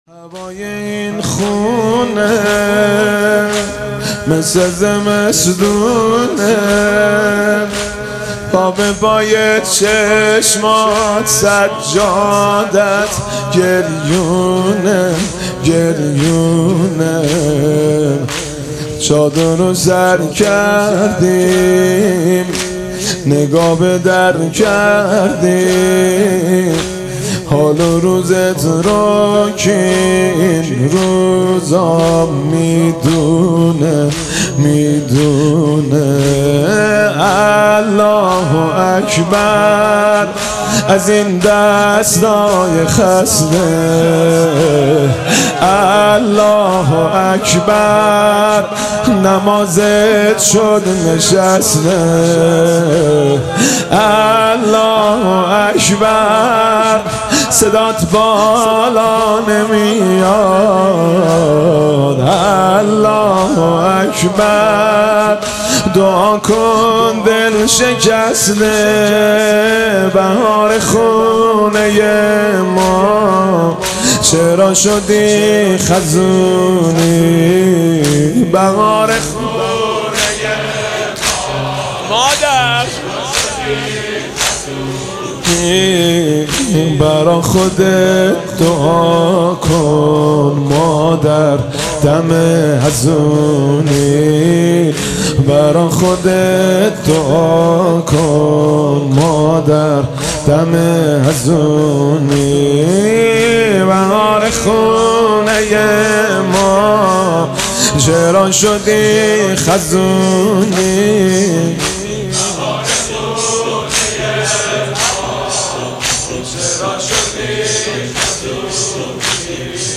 مداحی
دانلود مداحی جدید حاج مهدی رسولی شب اول فاطمیه دوم 25 دیماه 1399 هیئت ثارالله زنجان